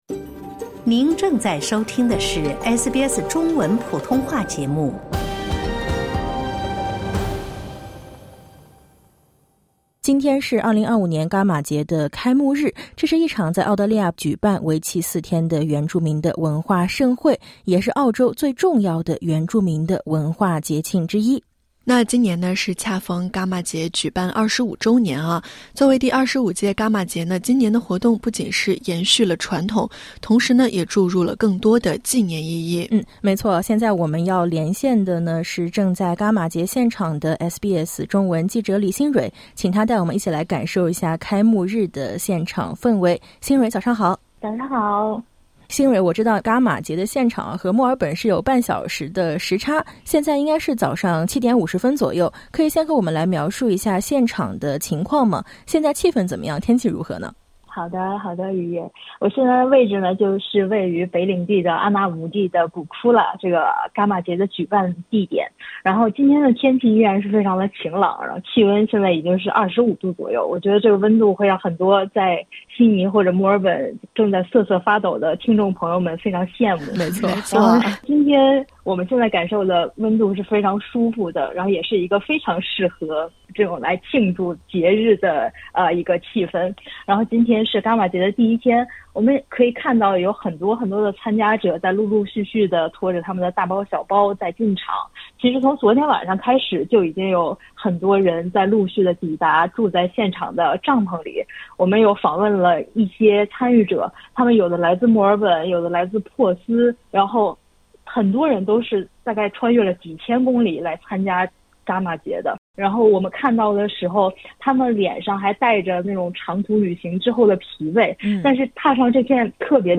第25届伽马节开幕日：SBS中文记者现场直击